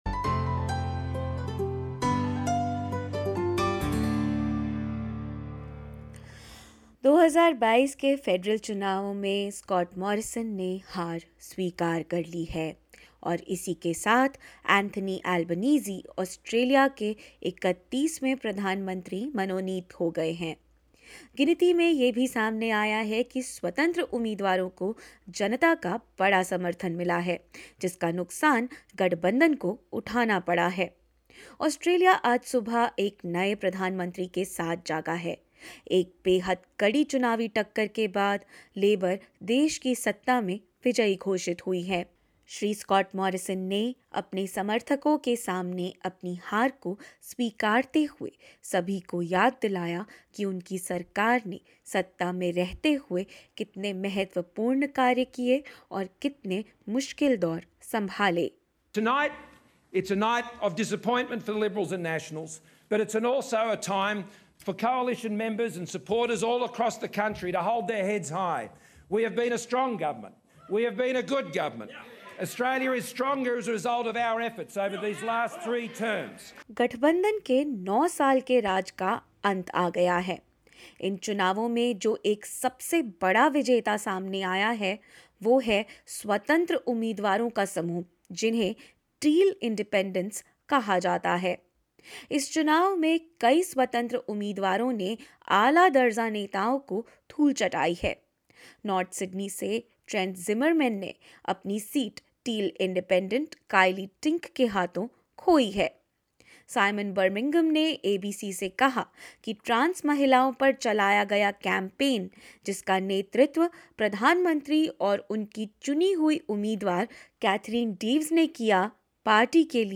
2022 के इस चुनाव में ग्रीन्स और स्वतंत्र उम्मीदवारों ने खेल पलट दिया। चुनावी गिनती में कौन हुआ पास, कौन हुआ फेल और कौन रहे अप्रत्याशित रूप से चौकाने वाले प्रत्याशी, जानेंगे इस रिपोर्ट में।